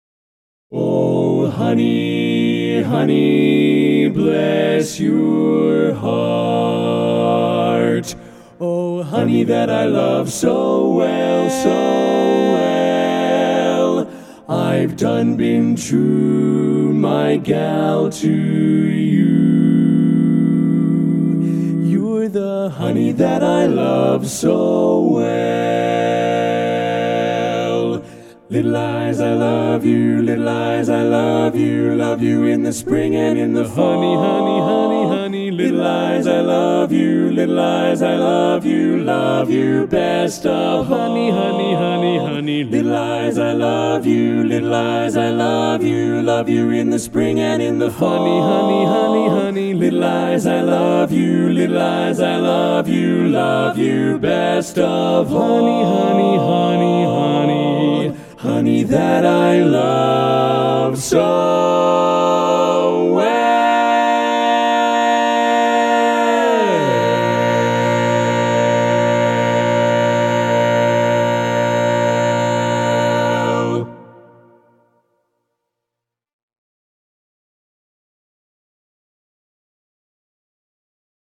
Barbershop
Full Mix